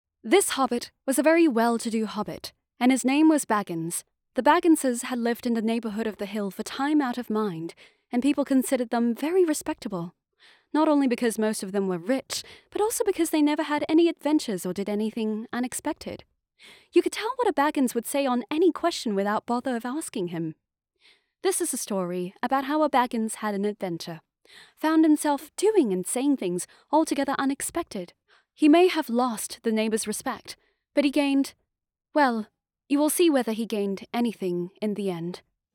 Young Adult, Adult
british rp | character